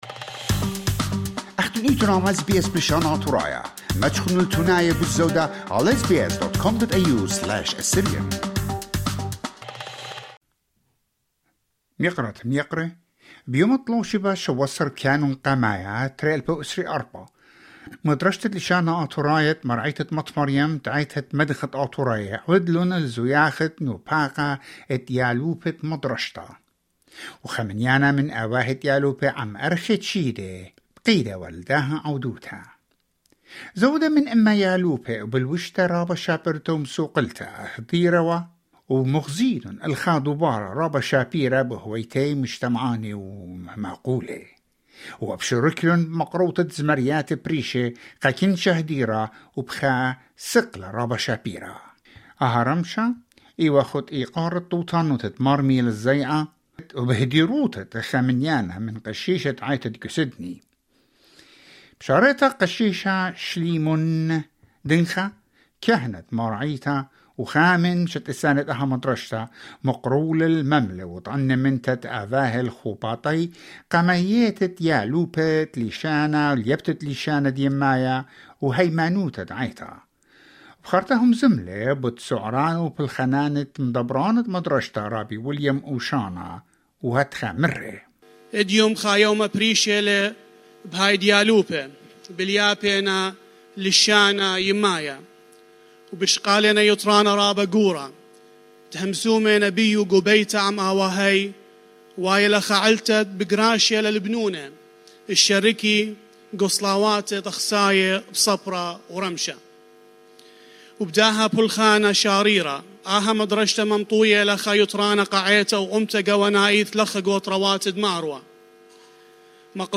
Over a hundred students from St. Mary's Church Assyrian Language School celebrated their graduation this year in a joyful ceremony.
The children delighted the audience with various song performances, adding to the festive atmosphere. Outstanding students were also recognized and awarded for their achievements.